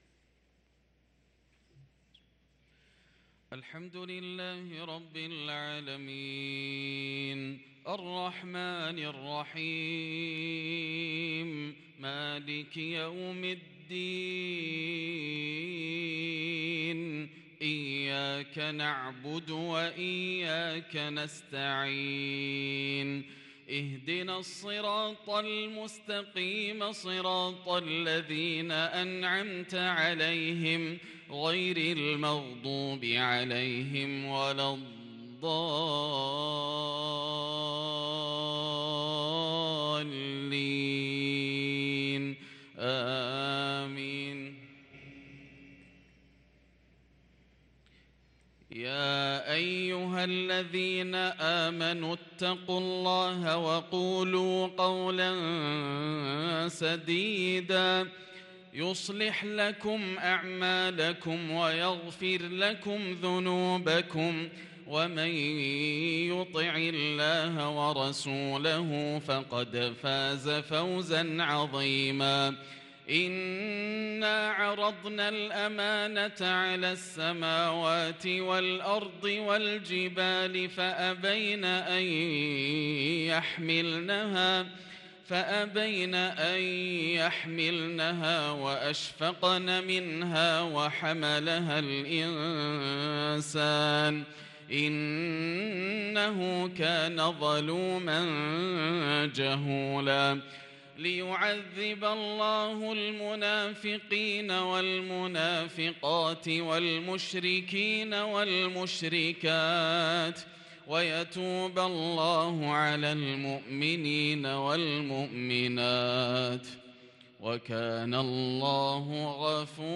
صلاة المغرب للقارئ ياسر الدوسري 2 صفر 1444 هـ
تِلَاوَات الْحَرَمَيْن .